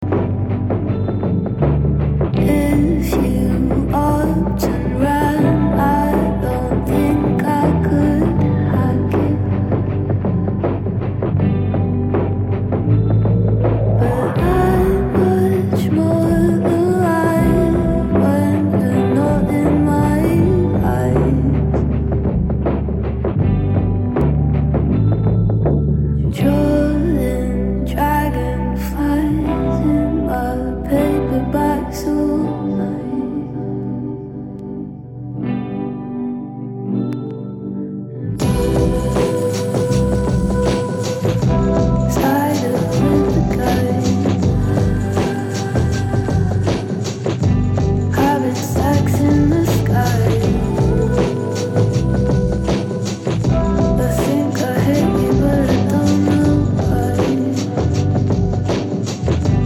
Sweet laid back back beats with quality lyrical content.